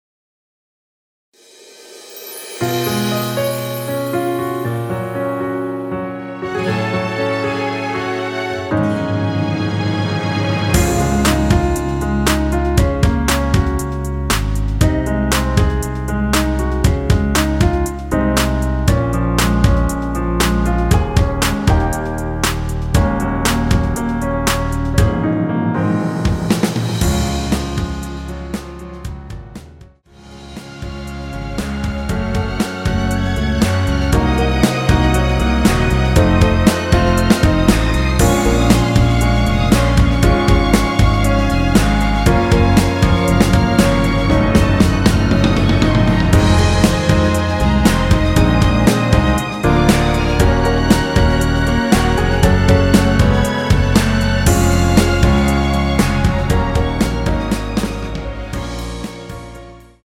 원키에서(-6)내린 MR입니다.
Db
앞부분30초, 뒷부분30초씩 편집해서 올려 드리고 있습니다.